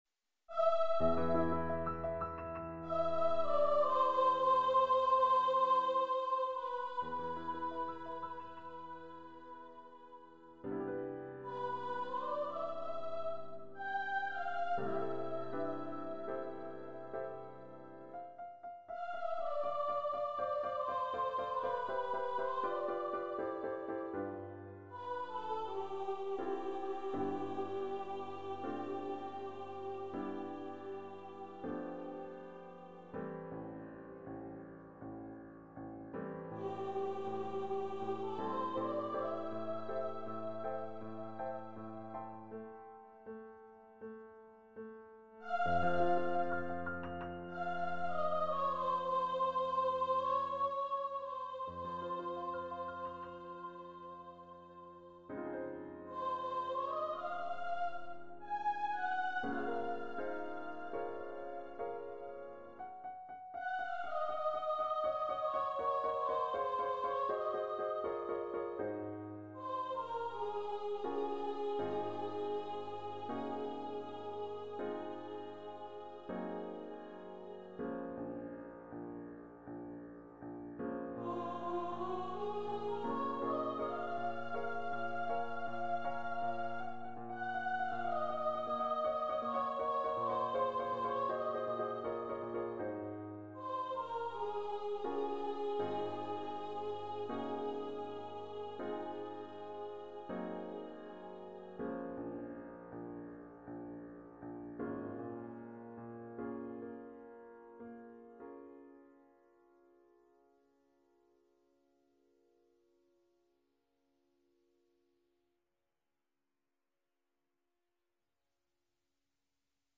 Voice and Piano
Composer's Demo